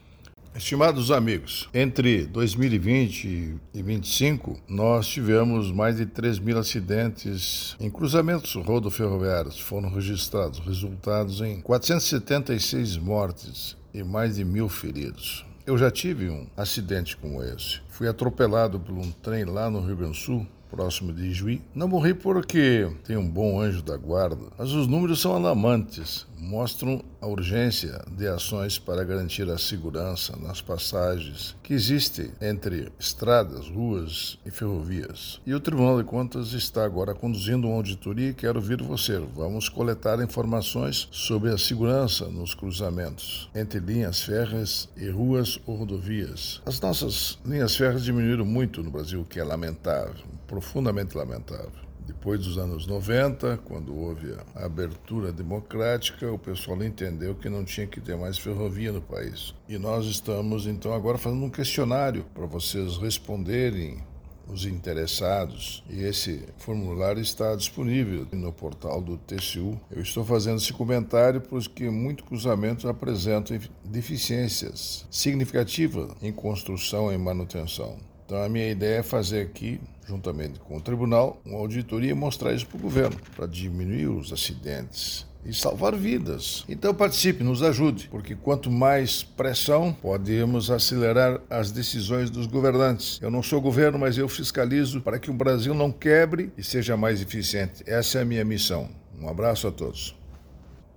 Comentário de Augusto Nardes.